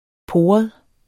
Udtale [ ˈpoːʌð ]